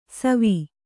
♪ savi